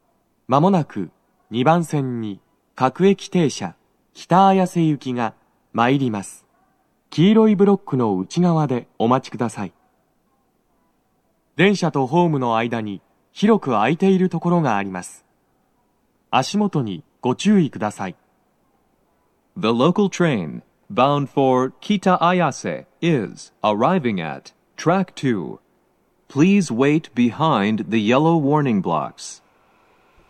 スピーカー種類 BOSE天井
鳴動は、やや遅めです。
男声
接近放送1